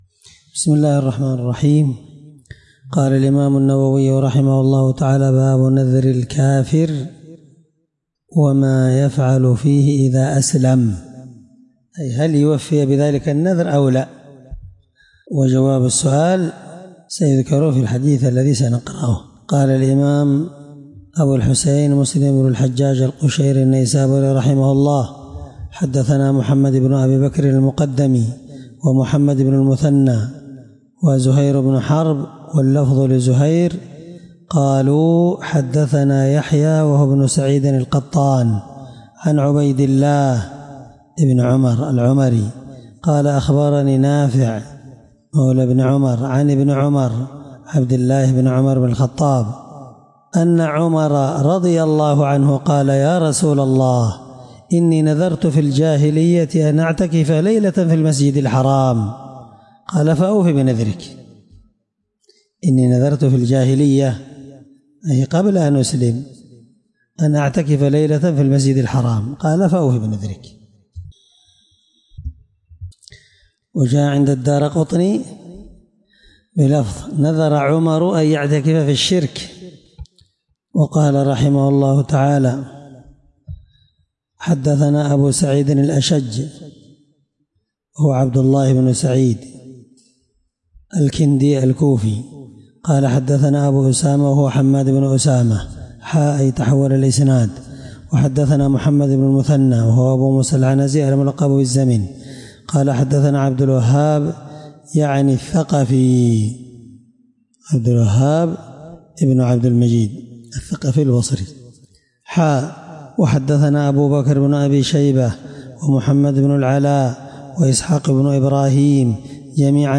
الدرس9من شرح كتاب الأيمان حديث رقم(1656) من صحيح مسلم